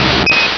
Cri de Kabuto dans Pokémon Rubis et Saphir.